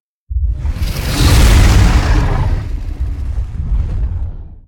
Sfx_creature_iceworm_swipe_01.ogg